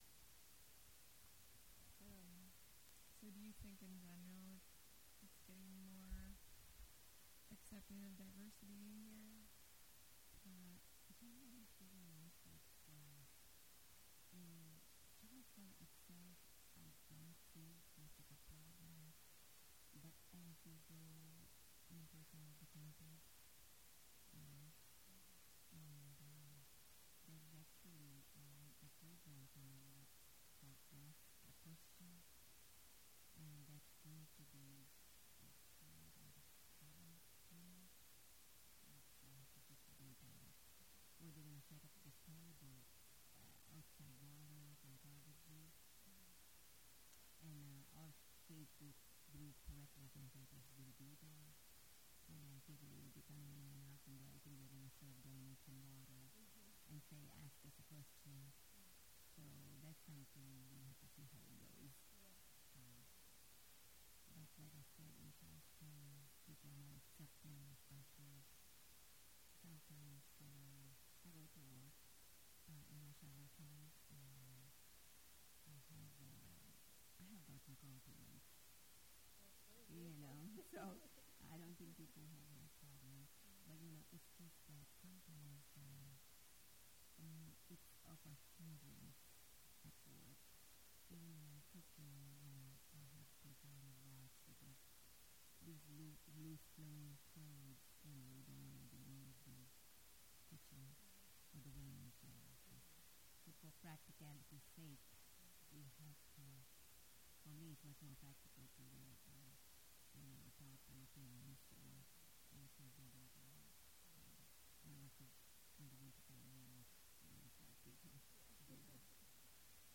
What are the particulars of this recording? Charleston (W. Va.), Madurai (India), and Kanawha County (W. Va.)